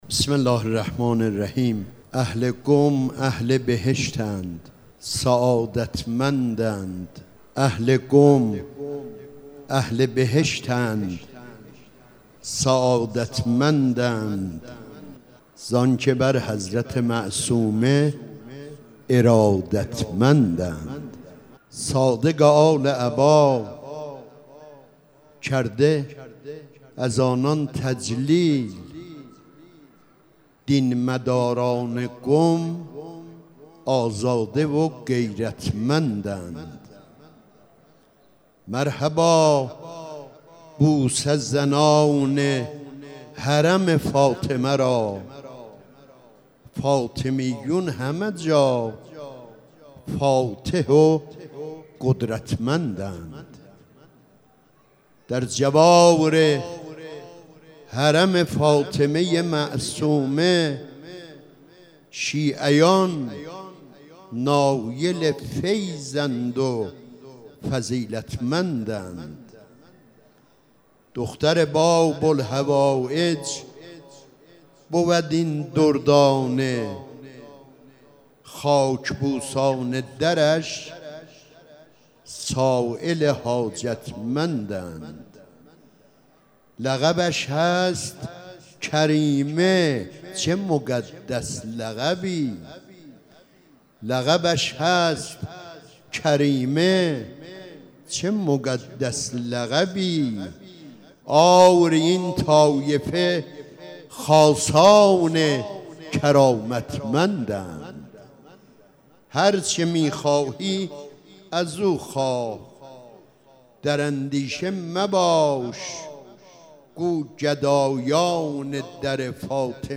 تکیه | شعرخوانی در وصف حضرت معصومه س